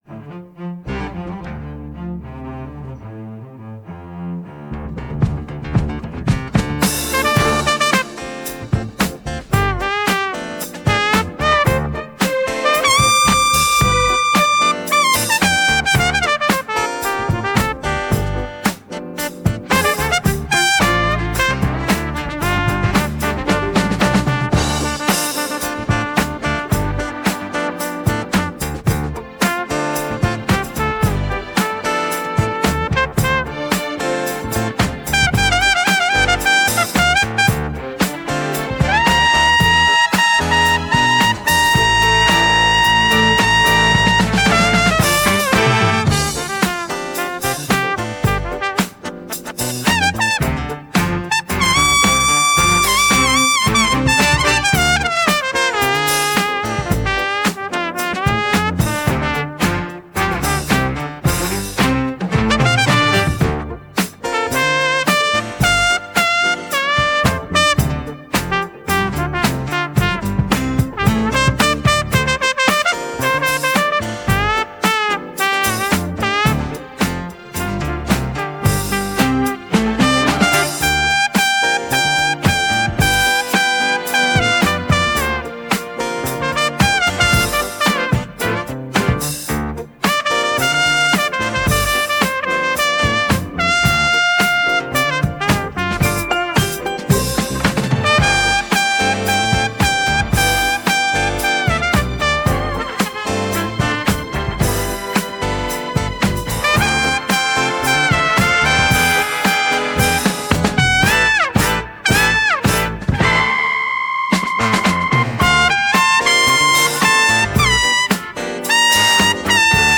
Некоторые удачные инструментовки с нейросетей и некоторые удачные варианты песенмем
08-dancing-in-the-rhythm(trumpet)..mp3